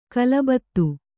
♪ kalabattu